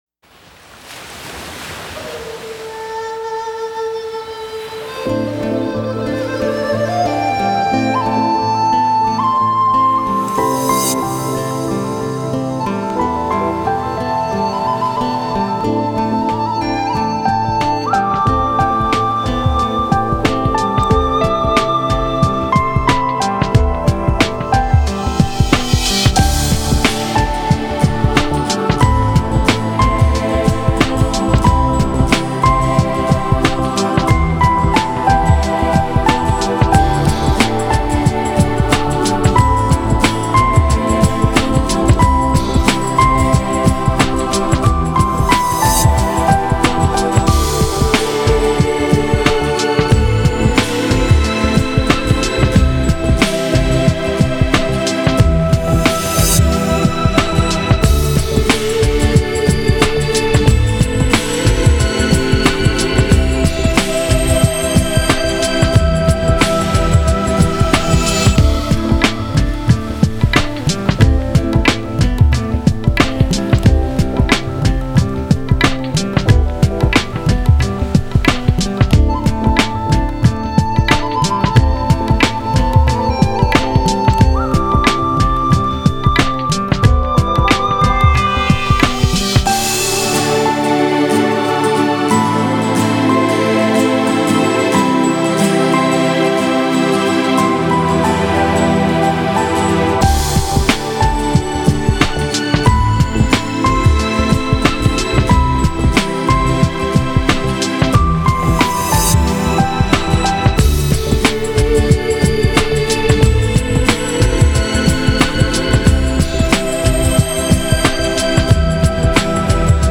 فوق العاده زیبا و آرامشبخش
نوع آهنگ: لایت]